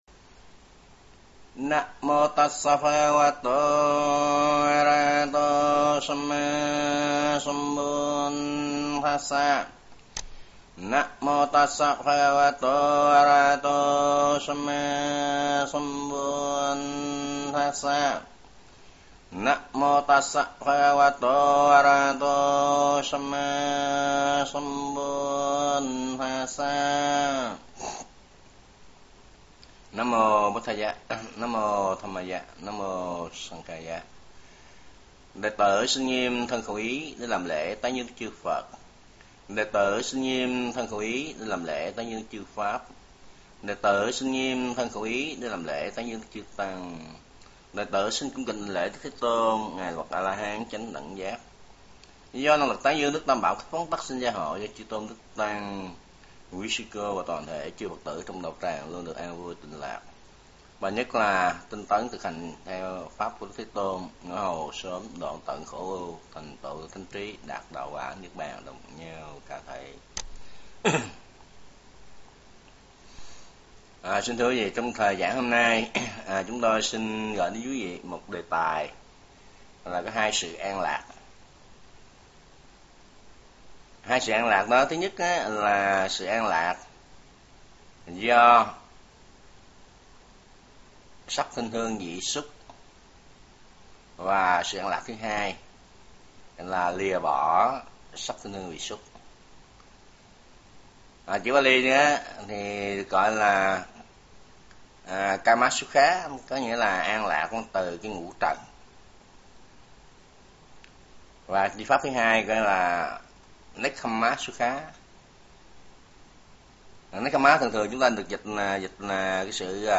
Những băng giảng Chư Tôn Đức Giảng Sư Phật Giáo Nguyên Thủy